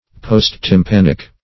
Search Result for " post-tympanic" : The Collaborative International Dictionary of English v.0.48: Post-tympanic \Post`-tym*pan"ic\, a. [Pref. post- + tympanic.]